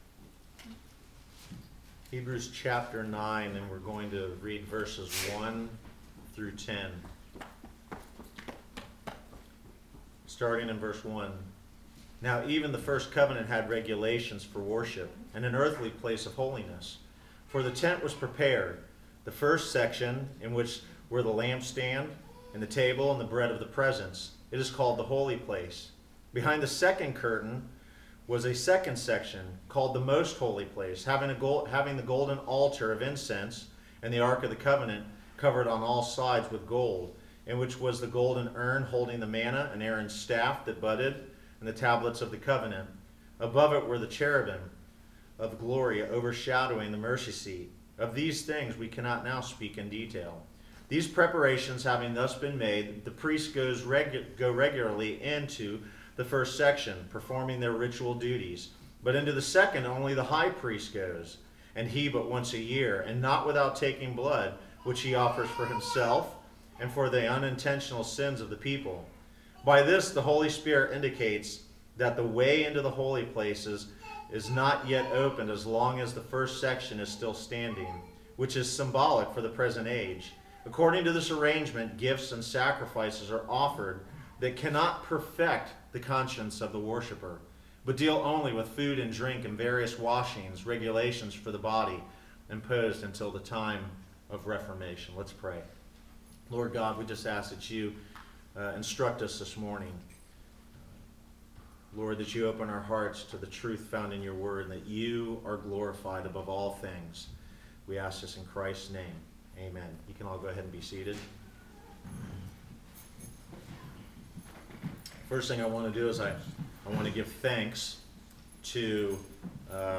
Passage: Hebrews 9:1-5 Service Type: Sunday Morning